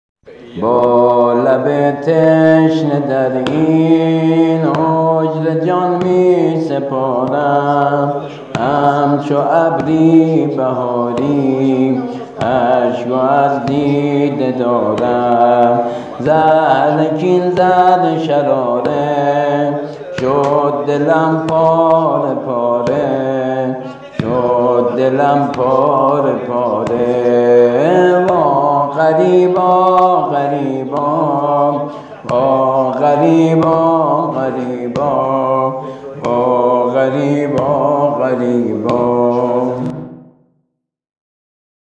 ◾نوحه سینه‌زنی